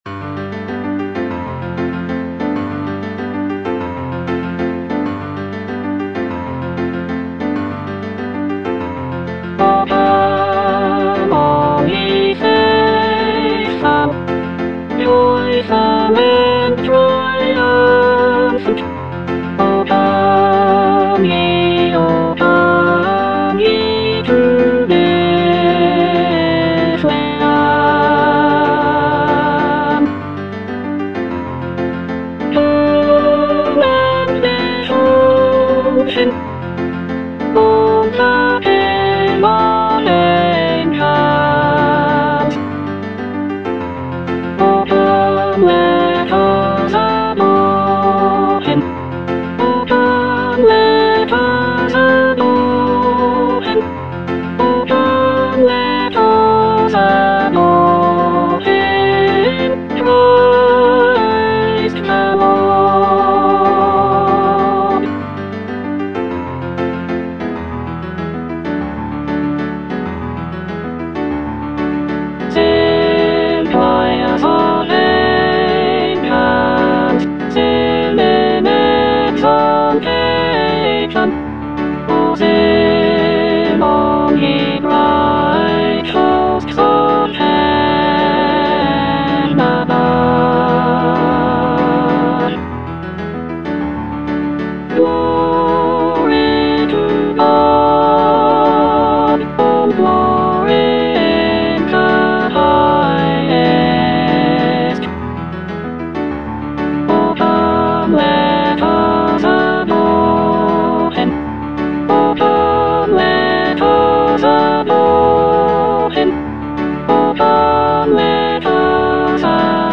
Soprano (Emphasised voice and other voices)